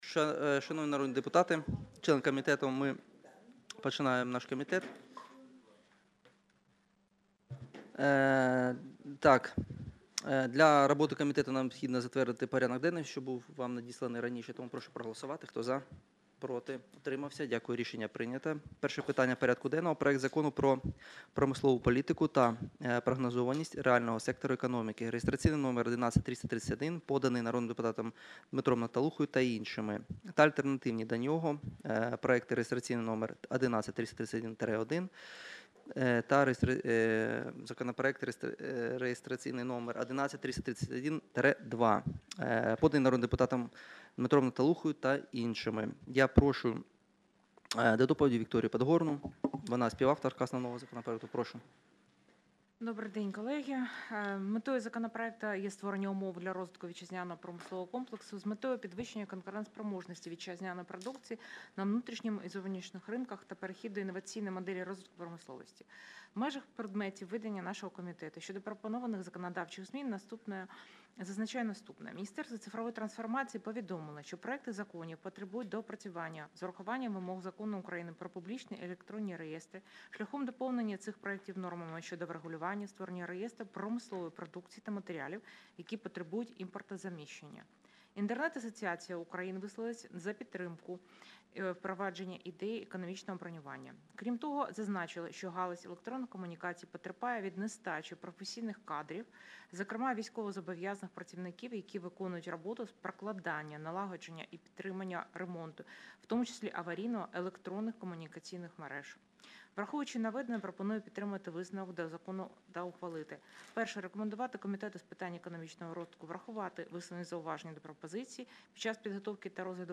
Аудіозапис засідання Комітету від 20.08.2024